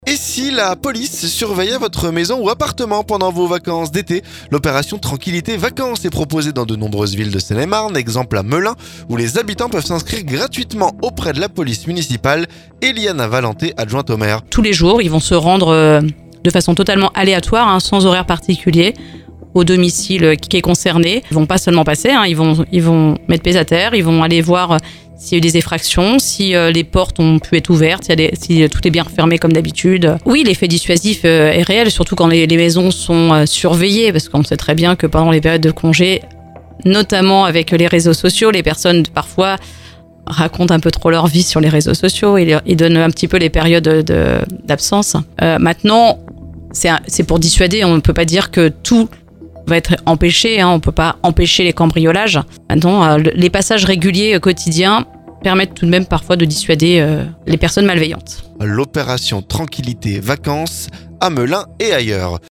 Exemple à Melun, où les habitants peuvent s'inscrire gratuitement auprès de la police municipale. Eliana Valenté adjointe au maire.